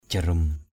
/ʥa-rum/ (d.) kim = aiguille. jarum jhik aw jr~’ J{K a| kim may áo.